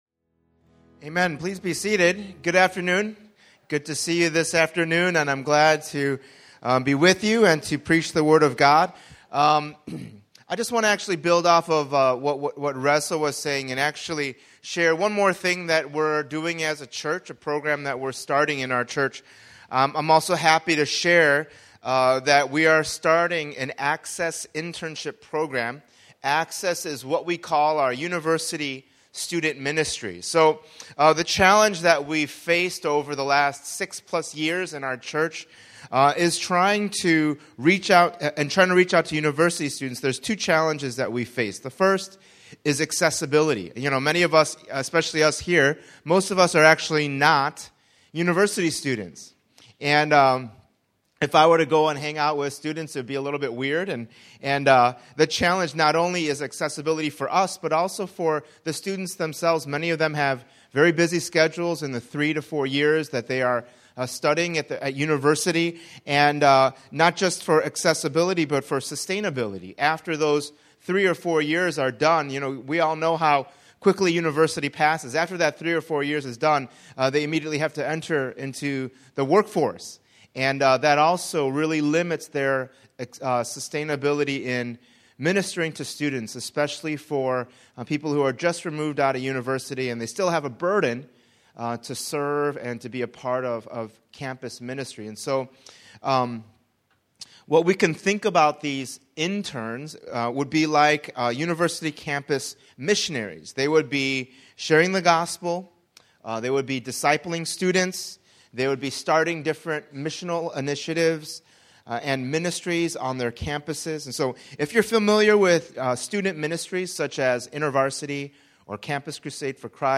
Download Audio Subscribe to Podcast Audio The Kingdom Series This sermon series called “The Kingdom” will go through the books of 1 and 2 Samuel.